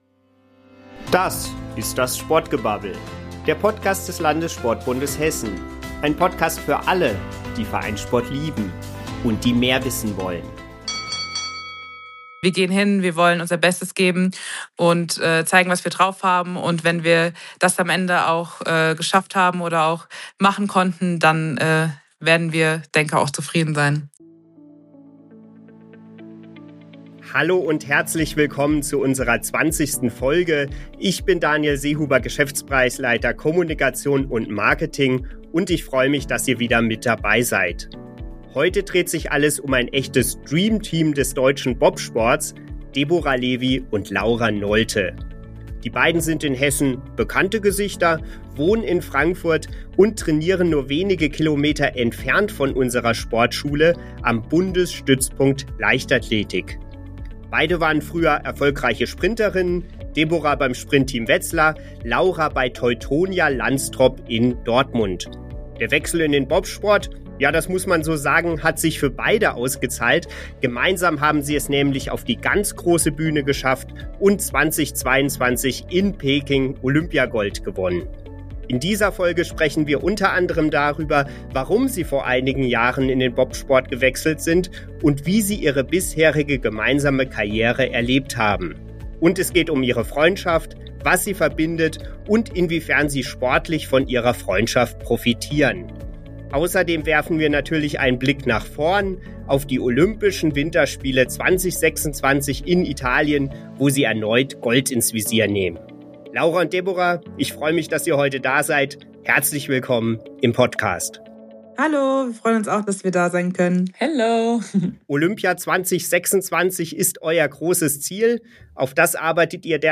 In der Folge sprechen die in Frankfurt lebenden Athletinnen aber nicht nur über Olympia. Es geht auch um ihre enge Freundschaft und was sie abseits des Sports miteinander erleben. Sie geben Einblicke in ihren Trainingsalltag am Bundesstützpunkt Leichtathletik. Und sie erläutern, wie sie den Bobsport für sich entdeckten.